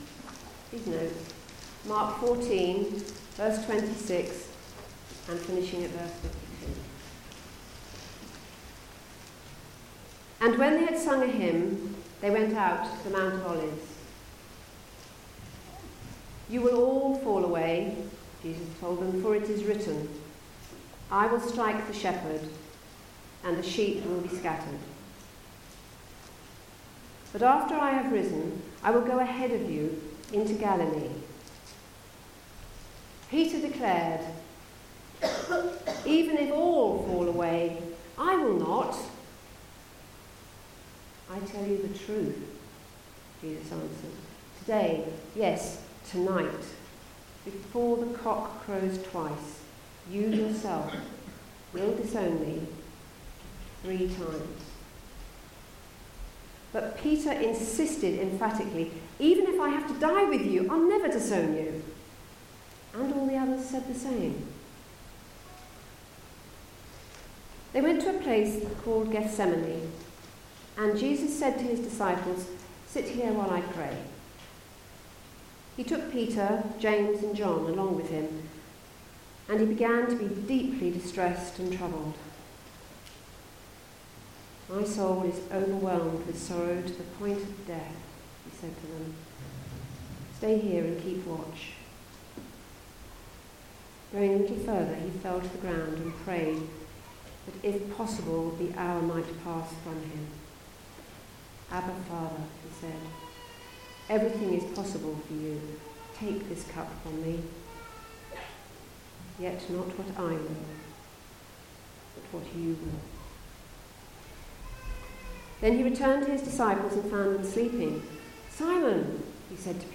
Sunday Service
Series: The Gospel of Mark Theme: The Road to Arrest Sermon